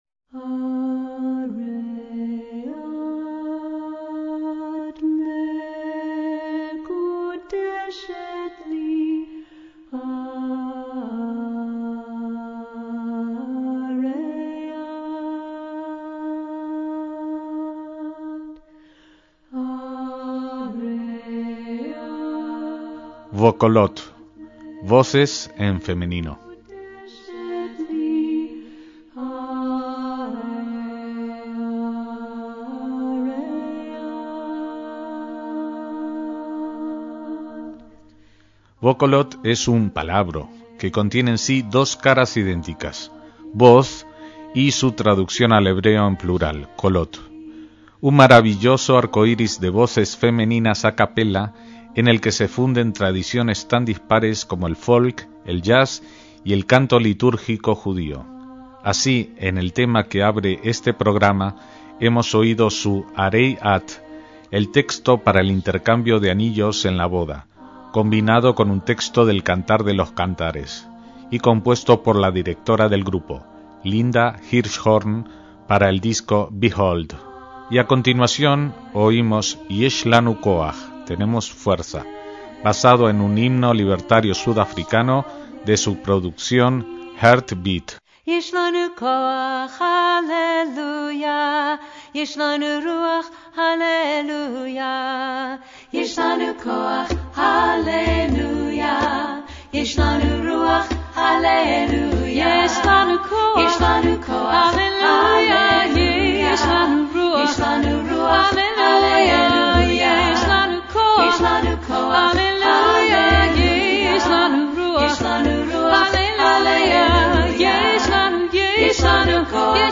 conjunto vocal femenino a cappella
percusionista de instrumentos orientales